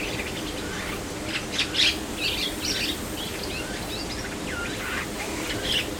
８月２８日東観察舎にて撮影   　頭上でムクドリがさえずっていました
さえずりはココ（６秒間）